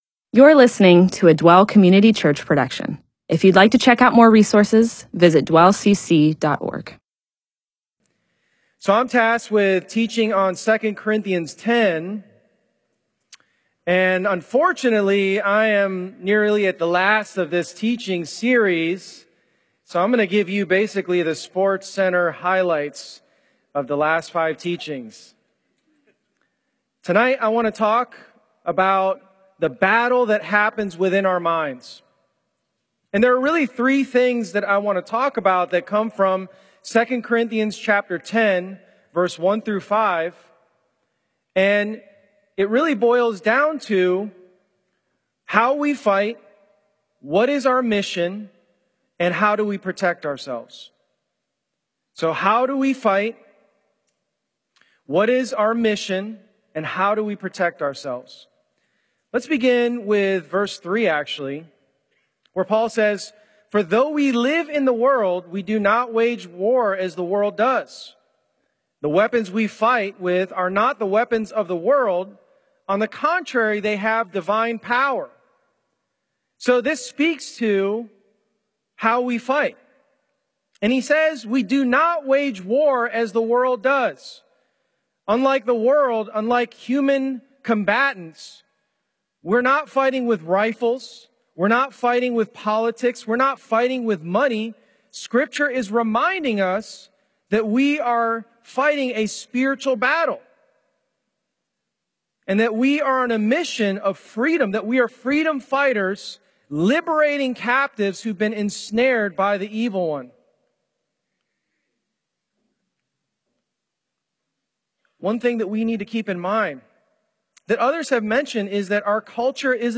MP4/M4A audio recording of a Bible teaching/sermon/presentation about 2 Corinthians 10:3-5.